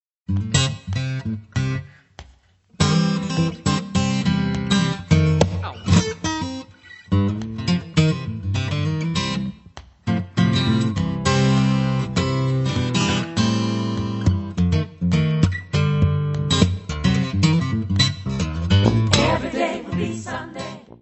drums
saxofones, flute
hammond b3, organ, keyboards
bass
piano, keyboards.
Área:  Pop / Rock